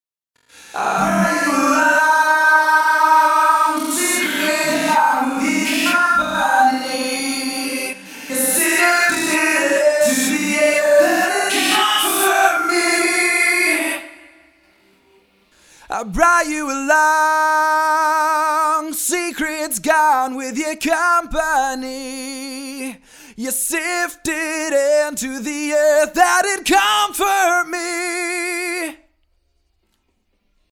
UltraTap | Vocals | Preset: Basement Brawl
UltraTap-Vocals-Basement-Brawl-Wet-Dry.mp3